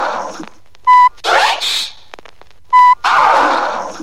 TOP >Vinyl >Grime/Dub-Step/HipHop/Juke
Side-B3 / Synth cuts